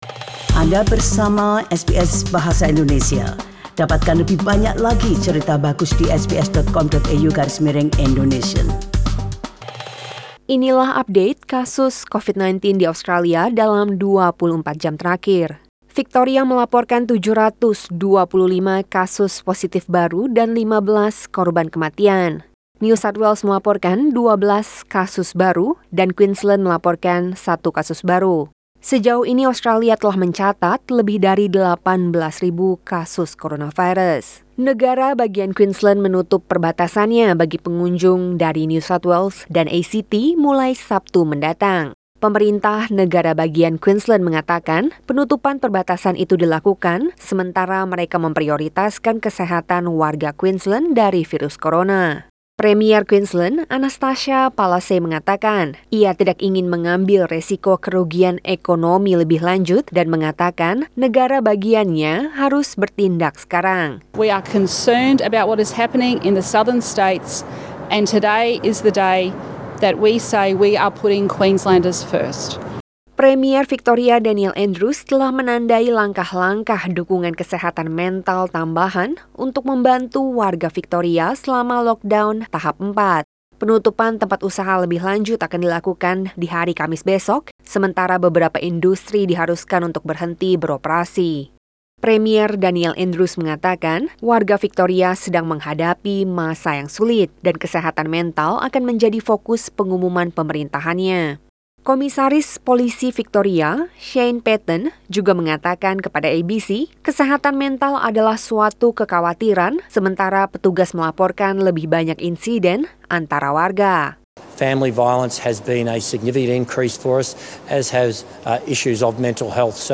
Warta Berita SBS Program Bahasa Indonesia - 5 Agustus 2020